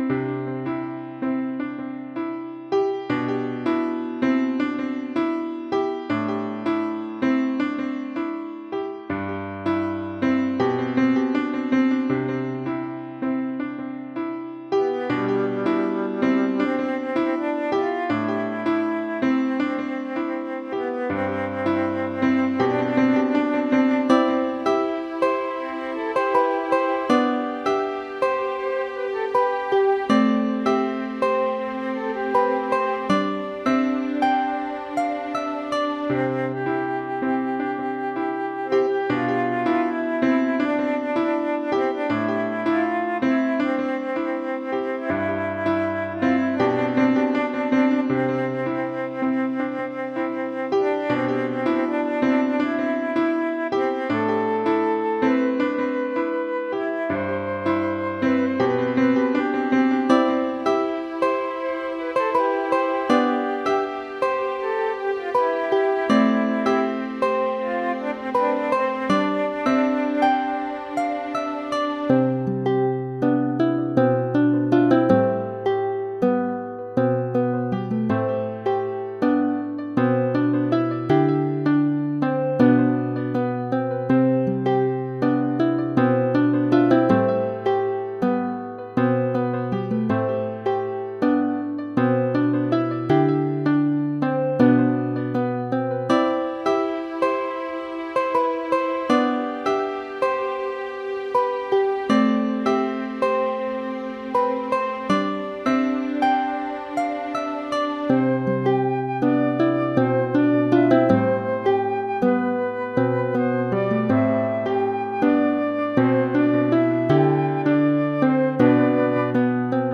A calm chill theme. Good for a chill maorning/evening theme or relaxing.